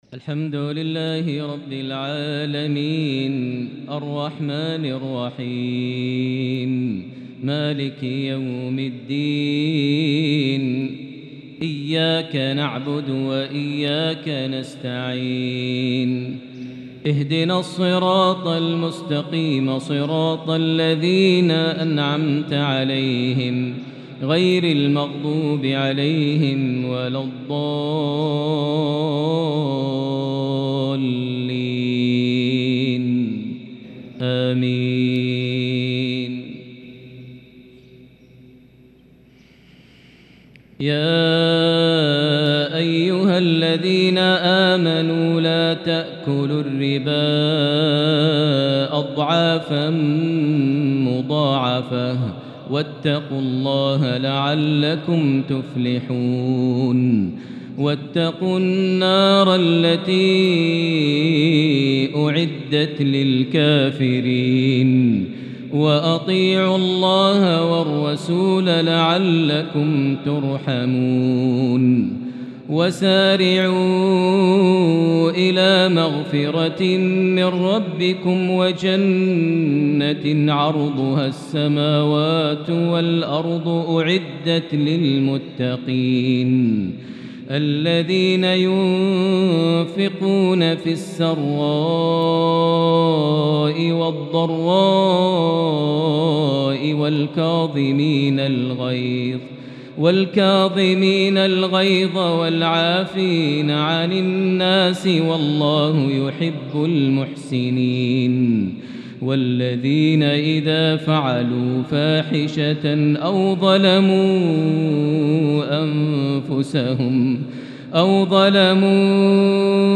fajr 6-7-2022 prayer from Surah Aal-e-Imran 130-145 > 1443 H > Prayers - Maher Almuaiqly Recitations